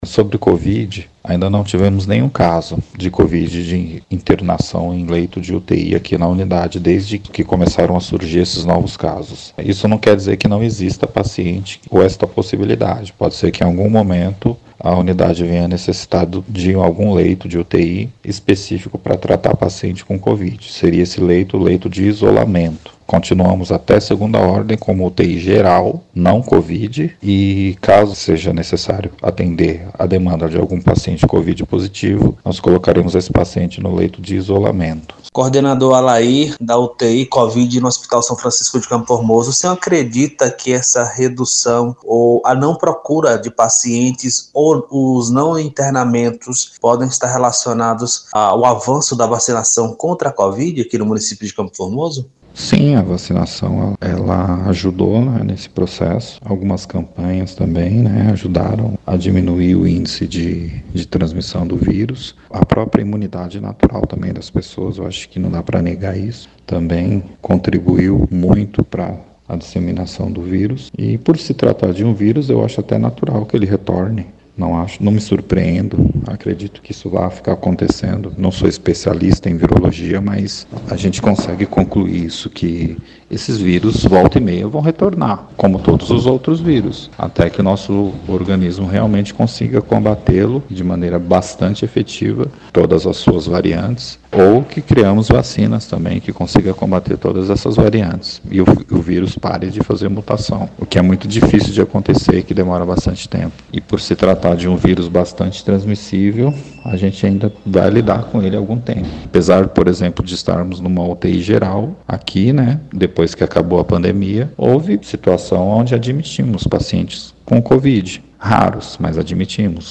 Em entrevista ao 98 Notícias, o secretário de saúde, Paulo Henrique Nascimento falou sobre a publicação de um decreto publicado na terça-feira (06) que determina o uso obrigatório de máscara no hospital e em unidades de saúde de todo o território municipal.